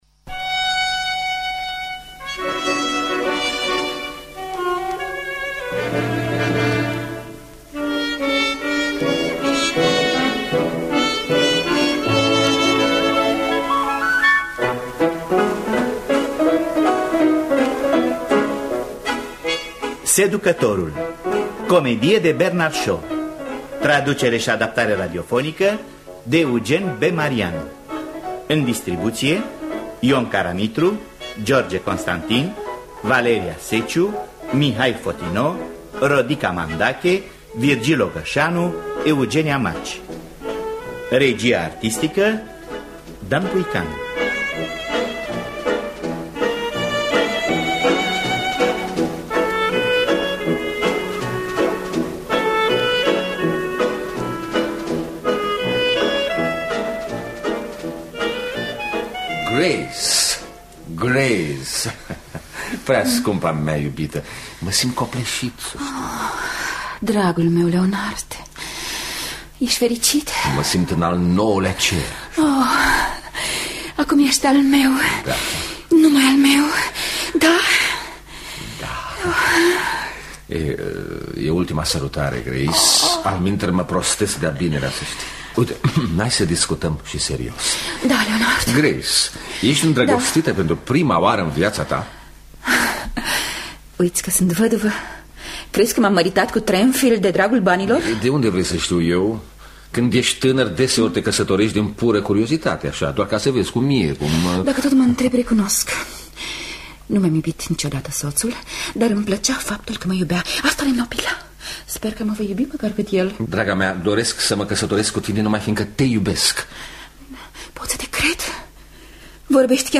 Traducerea şi adaptarea radiofonică